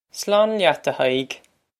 Pronunciation for how to say
Slawn lyat, a Hi-ig!
This is an approximate phonetic pronunciation of the phrase.